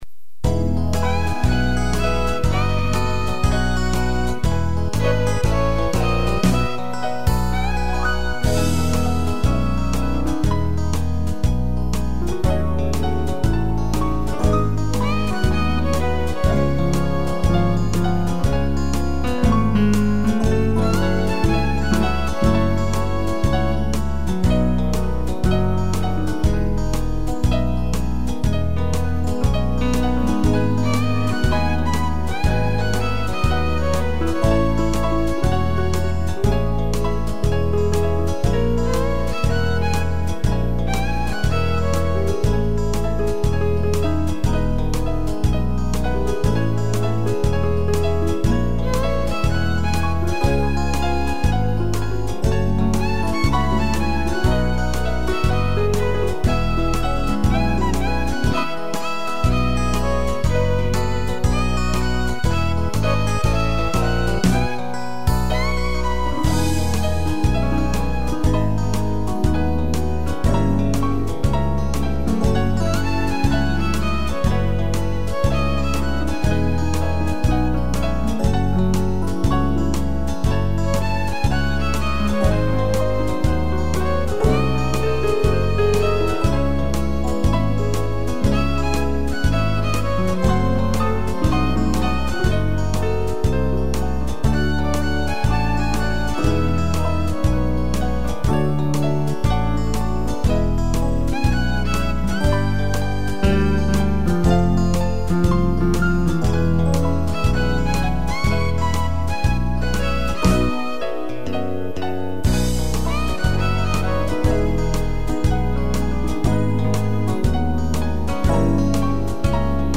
piano e violino
(instrumental)